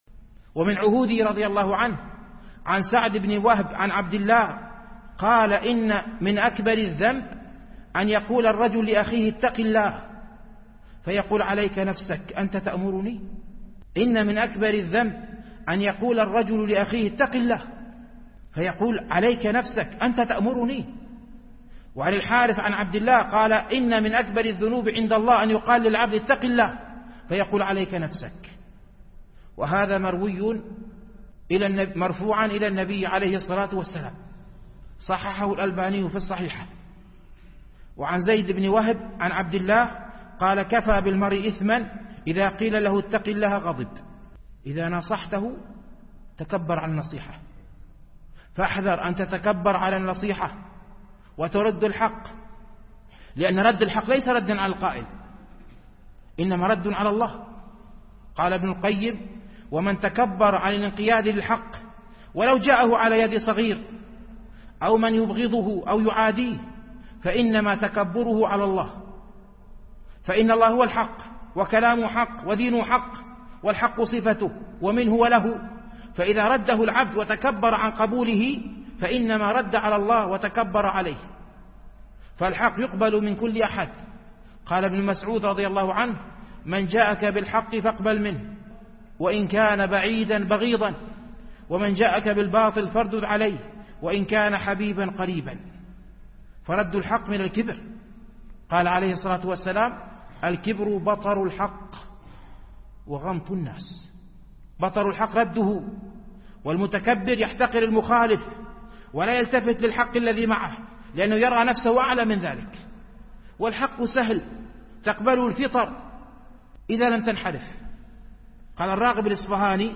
التنسيق: MP3 Stereo 22kHz 32Kbps (VBR)